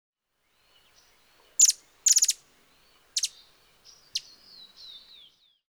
Голос колибри синебородой украшенной